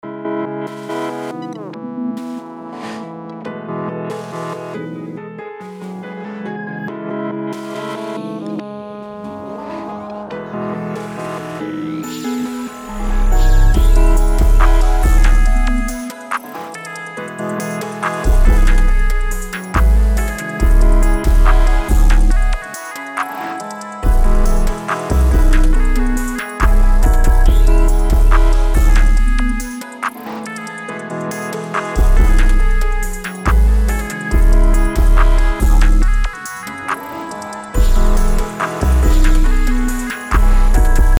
BPM: 140
Key: C# minor
Preview del beat: